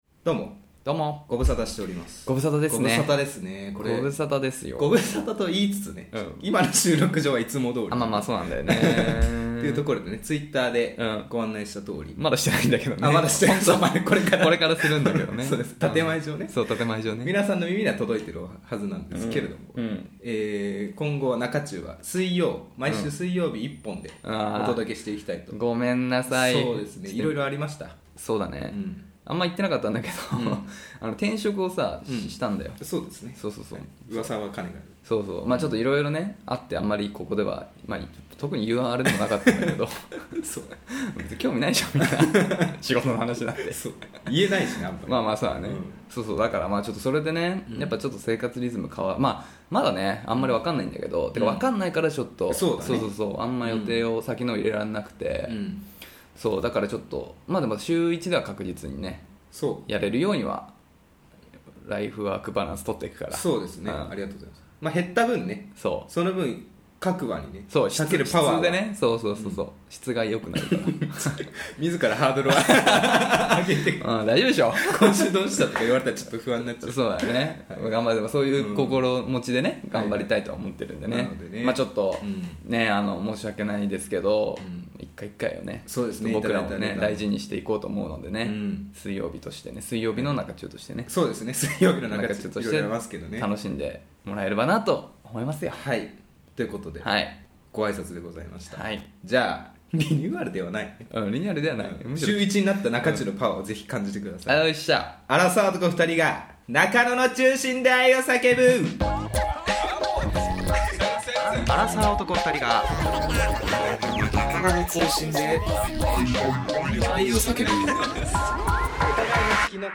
恋の街 中野よりアラサー男が恋愛トークをお届けします！